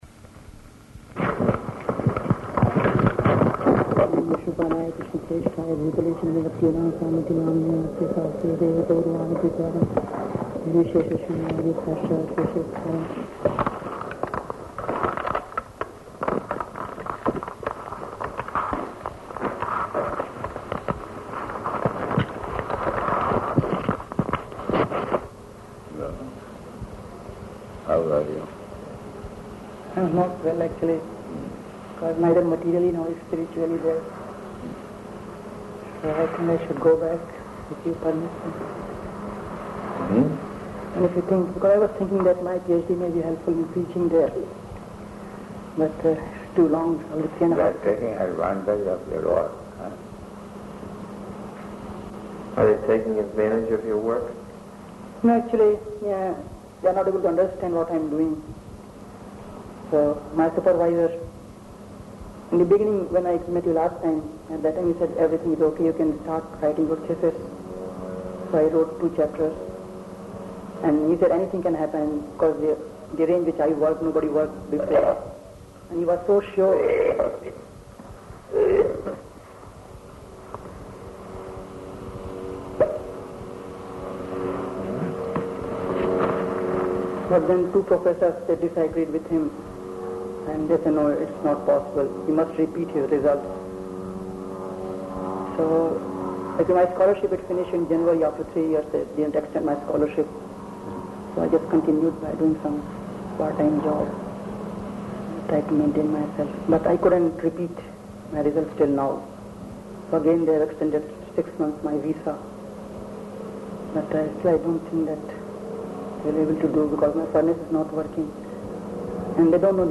Room Conversation
Room Conversation --:-- --:-- Type: Conversation Dated: August 29th 1977 Location: London at Bhaktivedanta Manor Audio file: 770829R1.LON.mp3 Indian devotee: [offers obeisances] Prabhupāda: So, how are you?